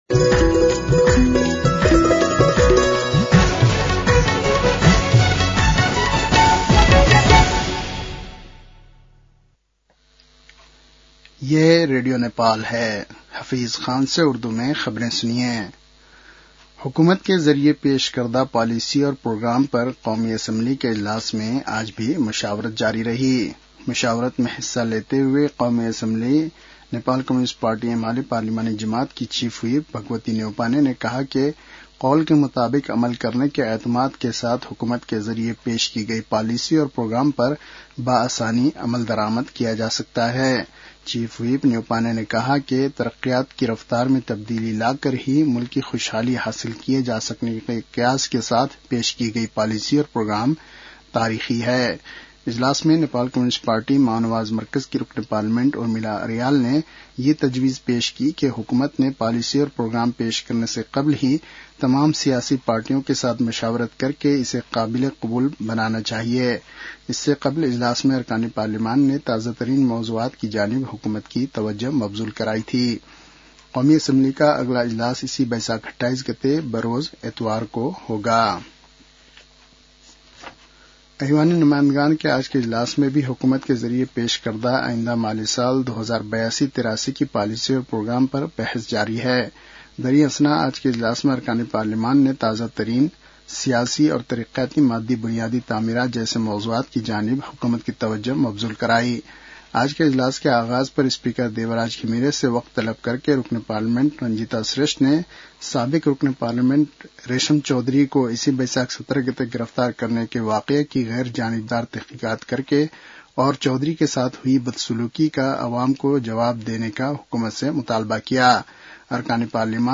उर्दु भाषामा समाचार : २३ वैशाख , २०८२